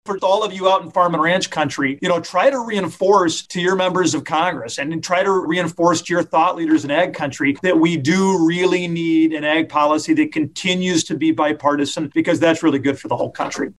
Agri-Pulse recently held a webinar to talk about the top issues facing rural America this year and what might be ahead in the 117th Congress for potential answers to those challenges.
One of the speakers was South Dakota Republican Congressman Dusty Johnson.